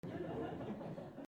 小さな笑い
/ M｜他分類 / L50 ｜ボイス
20人前後 D50